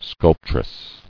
[sculp·tress]